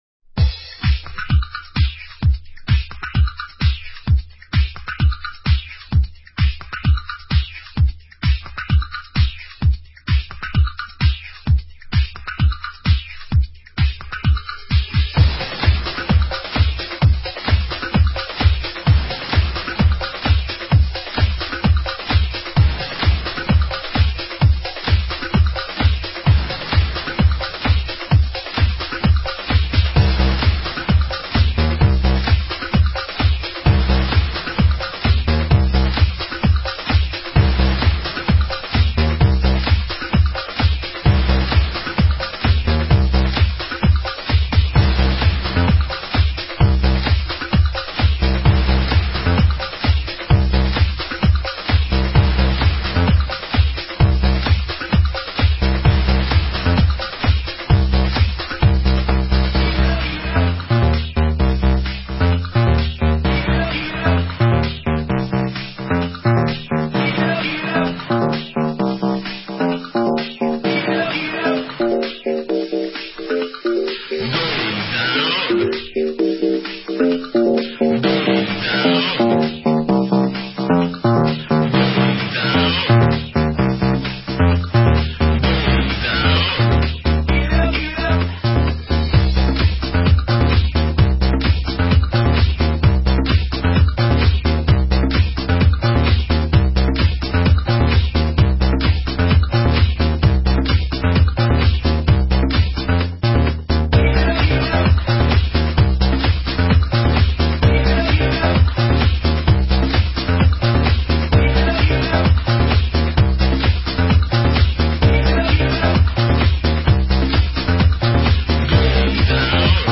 Но ритм и мелодия просто класс!